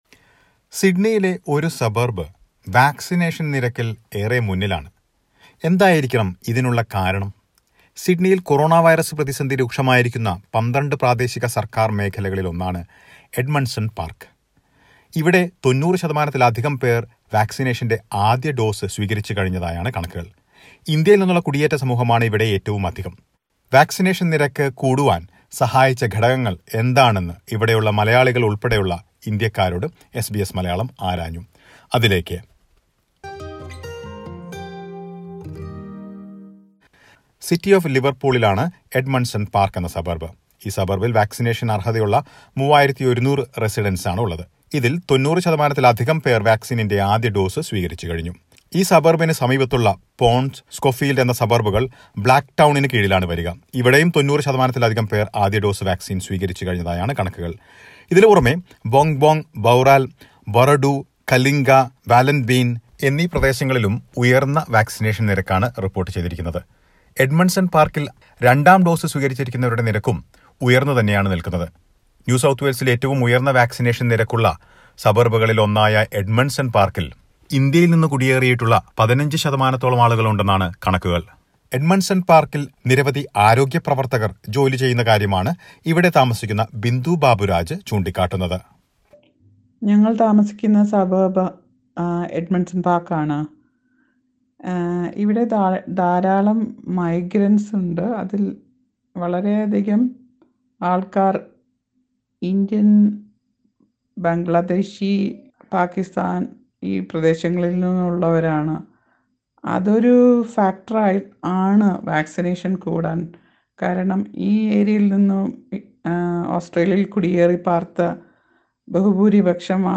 Listen to a report about Edmondson Park a Sydney suburb with a high number of Indian migrants and its high vaccination rate.